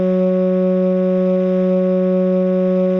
- Steam whistles now play a seamless continuous sound - Fixed an issue with the wrench and powered shafts 2022-05-08 19:49:23 +02:00 75 KiB Raw History
whistle_low.ogg